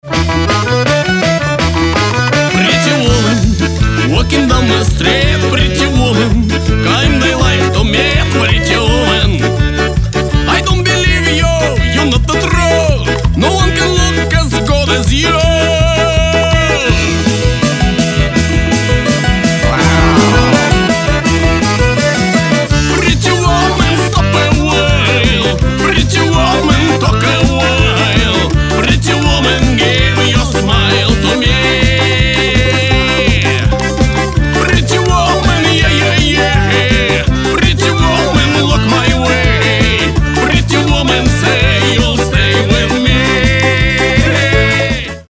• Качество: 320, Stereo
мужской вокал
забавные
веселые
Гармошка
балалайка
русские народные
кавер версия